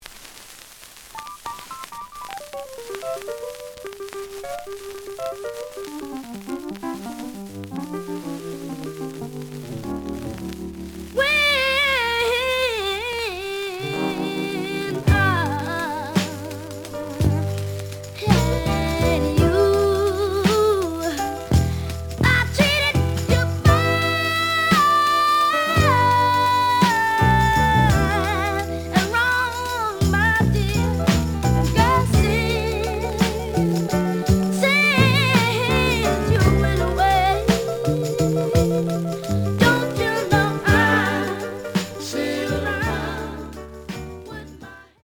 The audio sample is recorded from the actual item.
●Genre: Soul, 60's Soul
Some noise on B side.